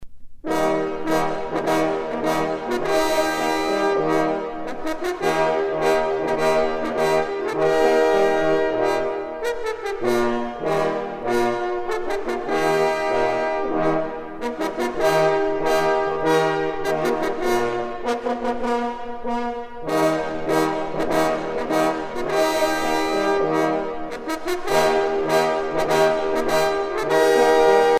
trompe - Fanfares et fantaisies de concert
circonstance : vénerie
Pièce musicale éditée